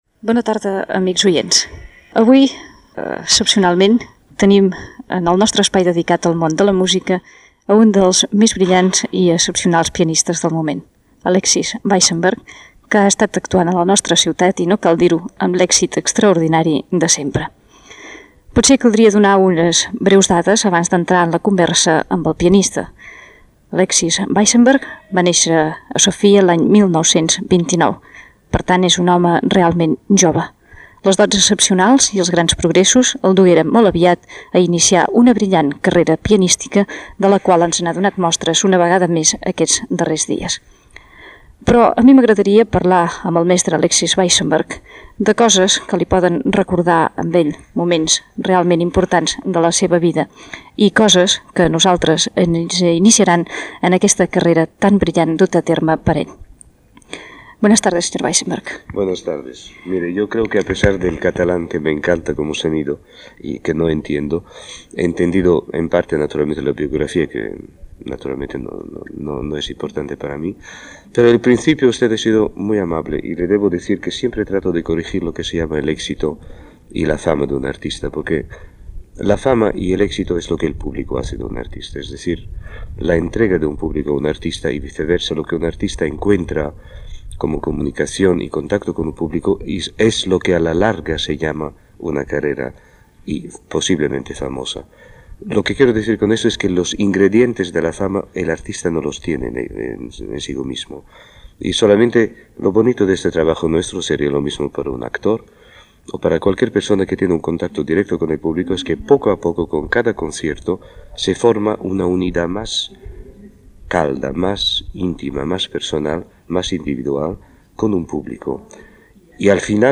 La música: entrevista Alexis Weissenberg - Ràdio 4, 1978